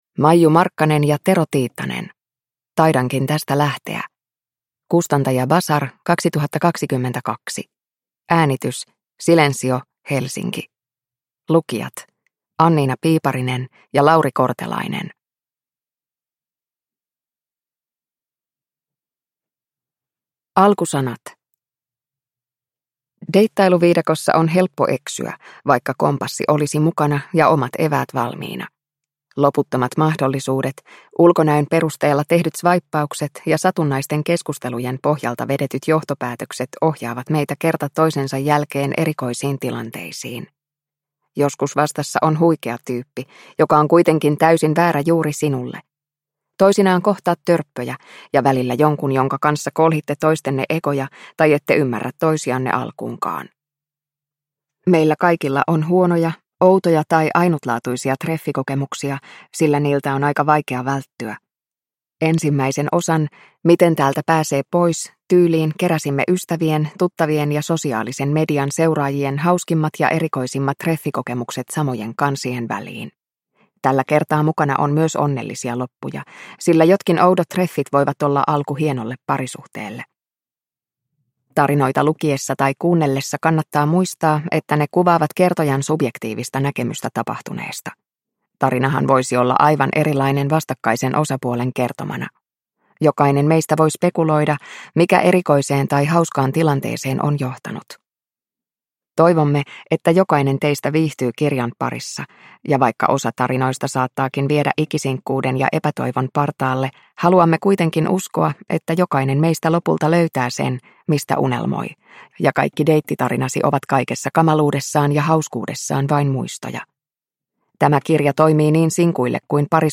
Taidankin tästä lähteä – Ljudbok – Laddas ner